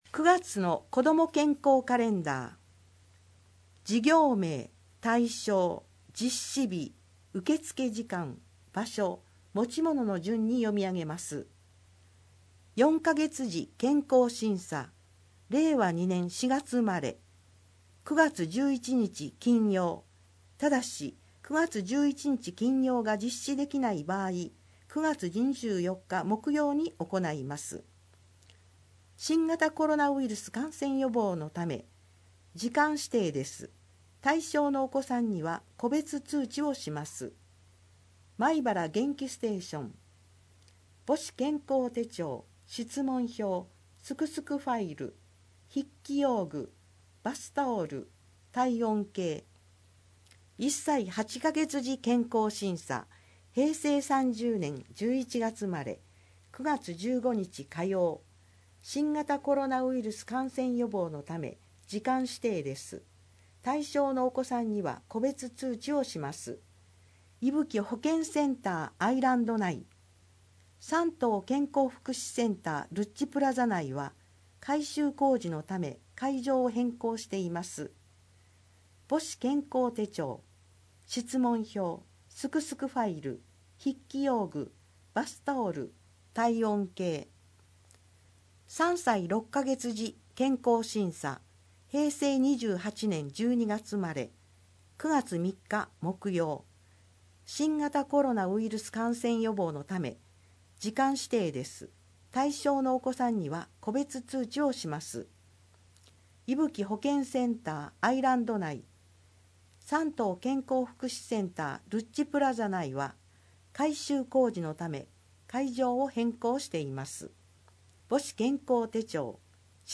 視覚障がい者用に広報まいばらを音訳した音声データを掲載しています。 音声データは音訳グループのみなさんにご協力いただき作成しています。